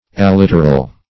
Alliteral \Al*lit"er*al\, a. Pertaining to, or characterized by alliteration.